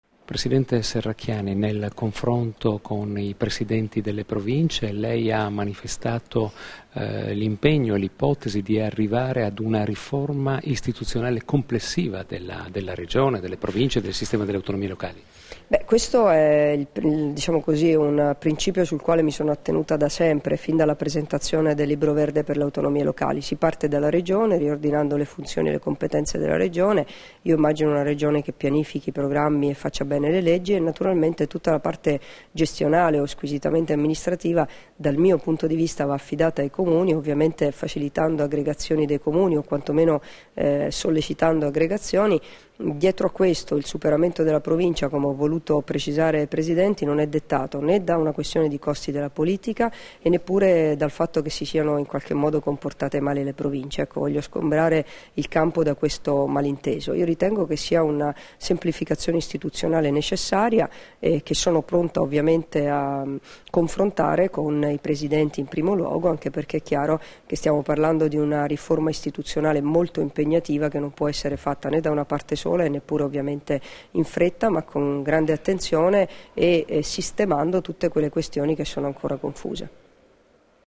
Ascolta le dichiarazioni di Debora Serracchiani rilasciate a margine dell'incontro con i presidenti delle quattro Province del Friuli Venezia Giulia, a Pordenone il 12 giugno 2013 - Formato MP3 [1300KB]